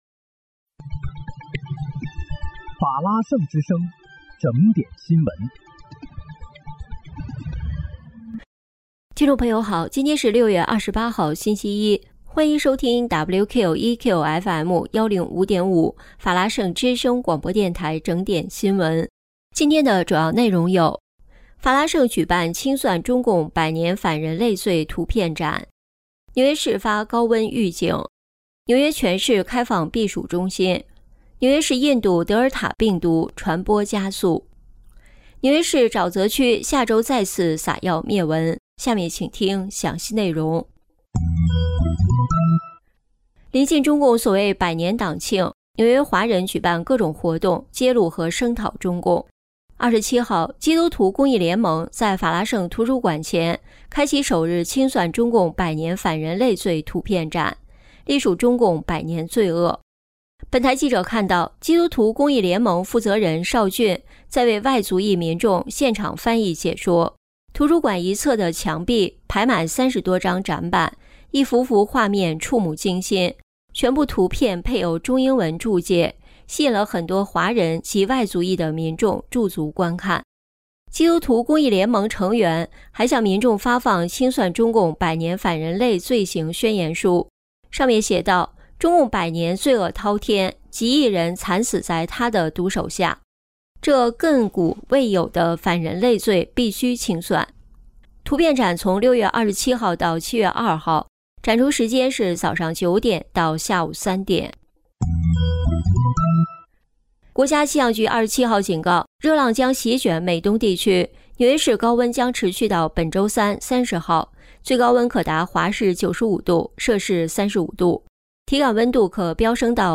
6月28日（星期一）纽约整点新闻